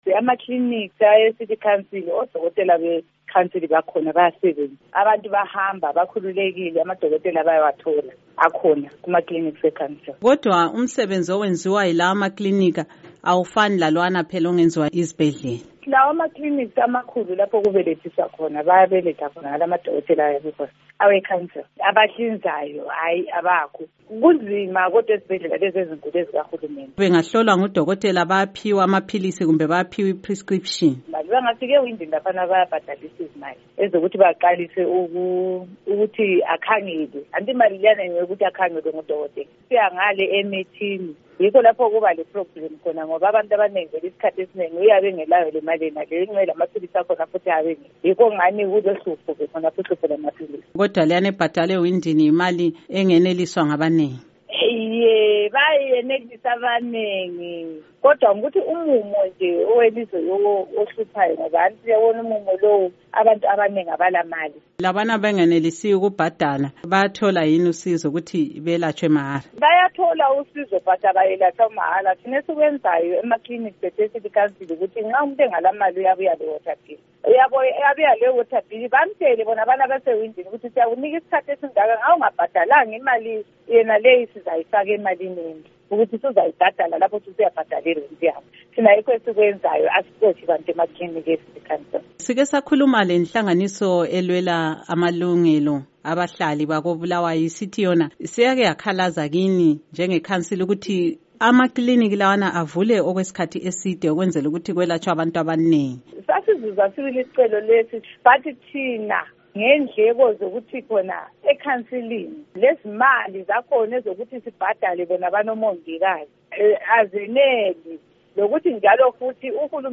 Umgcinisihlalo wekhomithi ebona ngezempilakahle kulikhansili, ukhansila Lilian Mlilo utshele lumsakazo ukuthi banengi abantu abathola usizo kubodokotela bakamasipala.
Ingxoxo loNkosikazi Lilian Mlilo